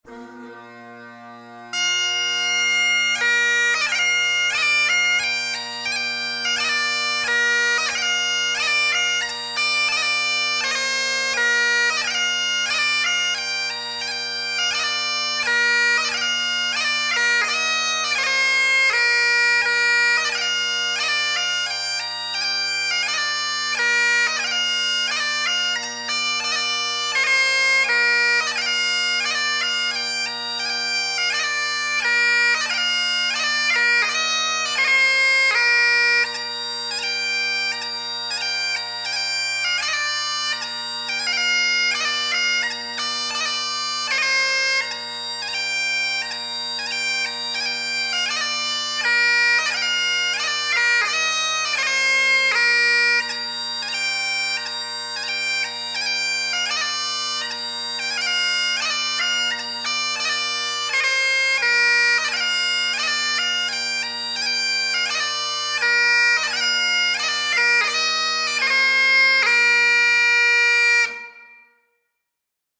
Scottish and Irish Bagpipe Music
3/4 (Retreat) March   Bengullion